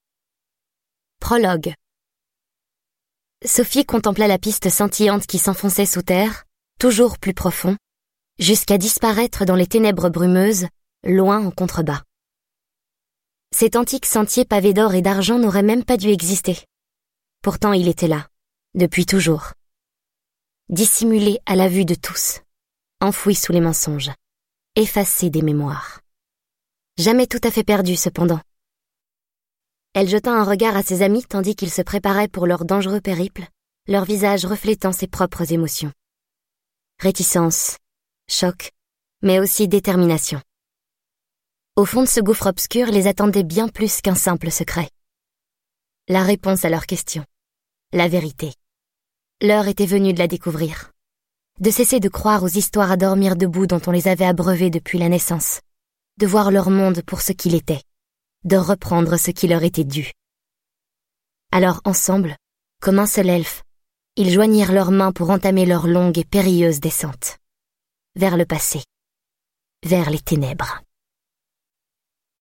Le sixième tome de la série à succès de Lumen, maintenant à l'audio !